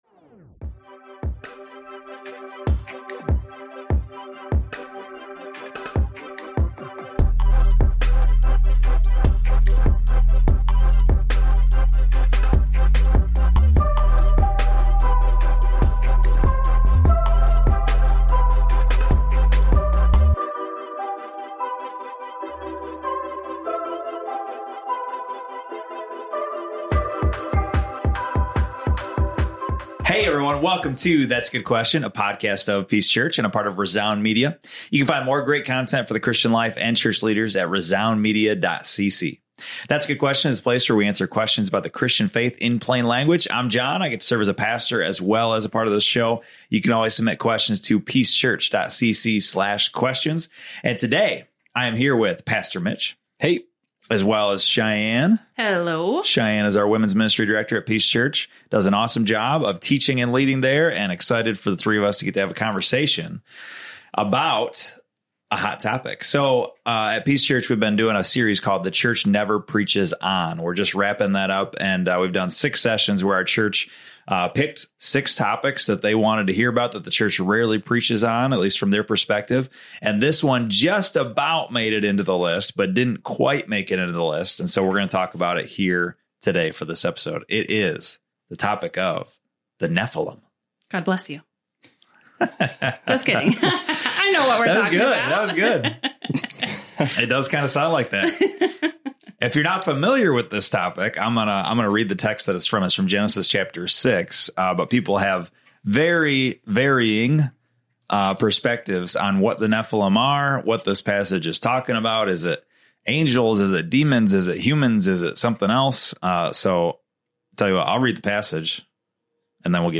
Who were these beings from Genesis? Tune in as the three discuss common interpretations and dive into what the Bible truly says about these mysterious beings.